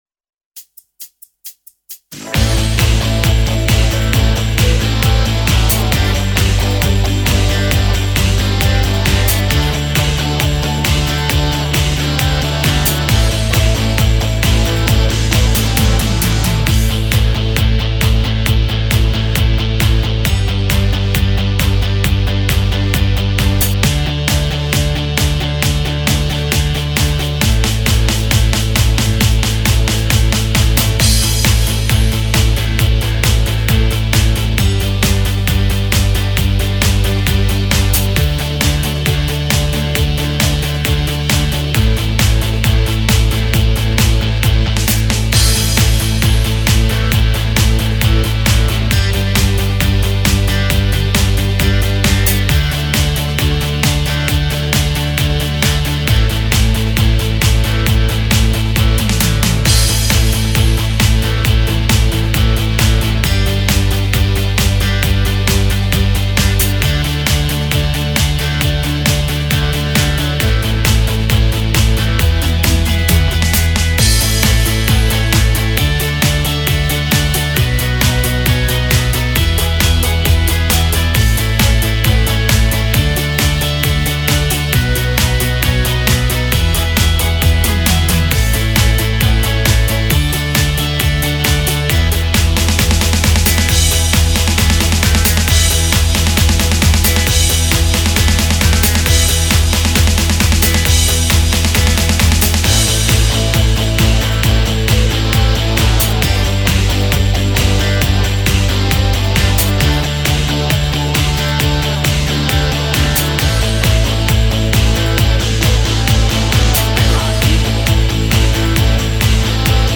Mp3 pesnička zahraná so štýlom z Korg Pa4X